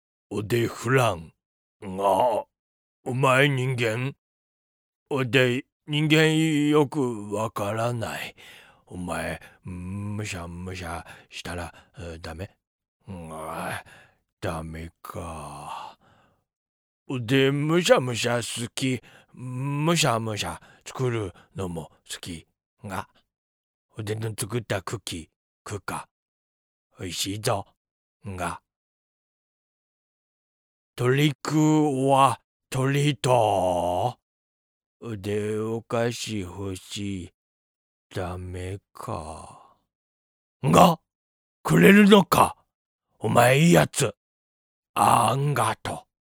フランケンシュタインの青年。
体は大きいけどまだ生まれたての子供みたい